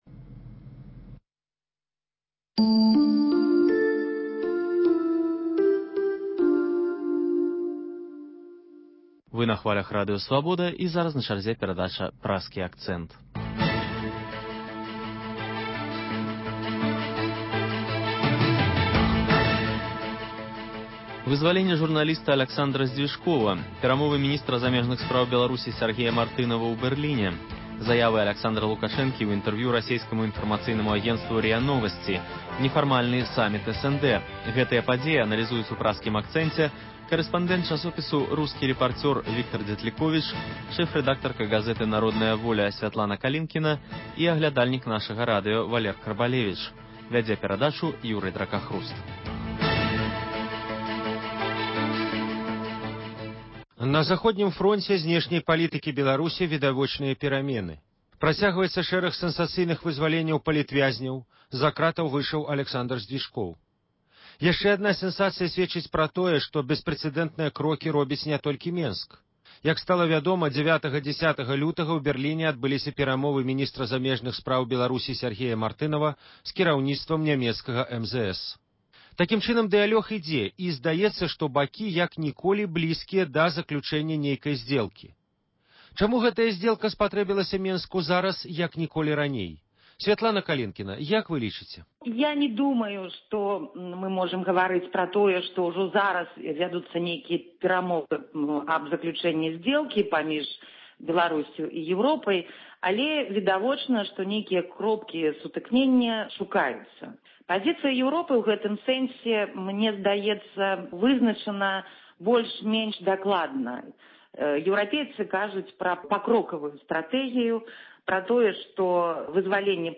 Круглы стол крытыкаў, прысьвечаны 115-годзьдзю з дня нараджэньня Максіма Гарэцкага.